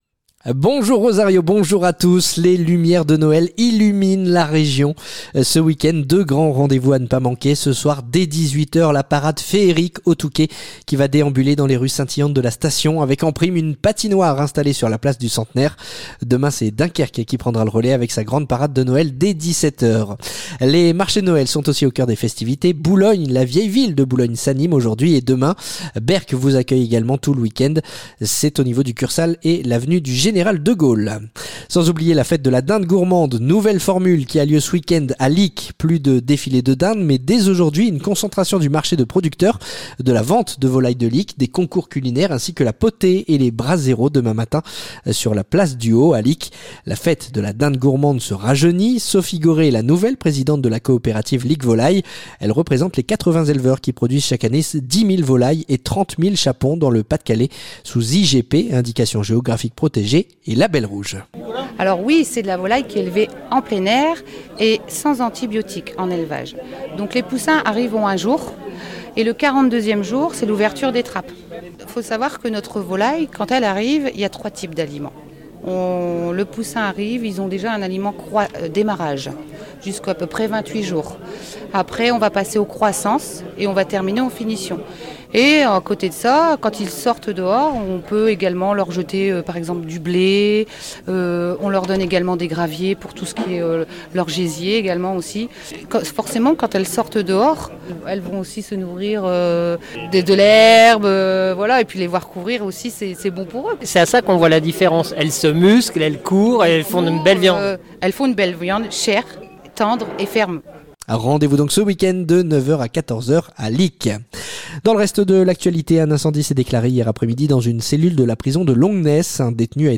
Le journal du samedi 13 décembre